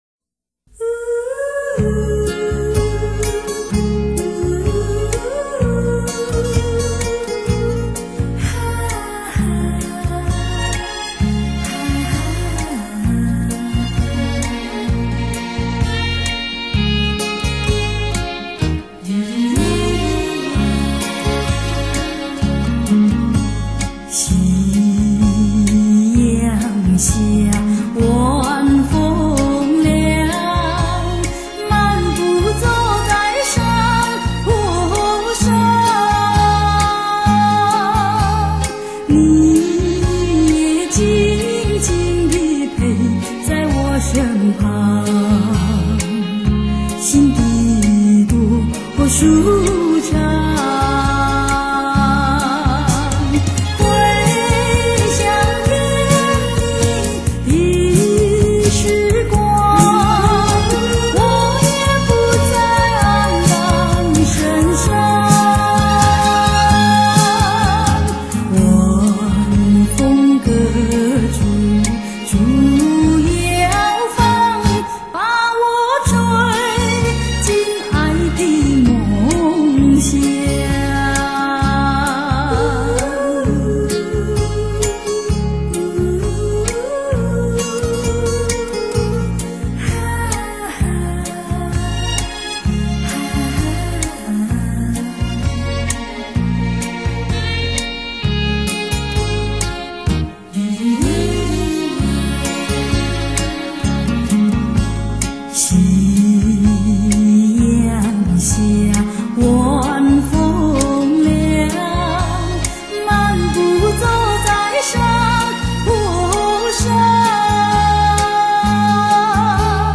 观众中不论老、中、青、鲜有不被她得天独厚的磁性嗓音所感动。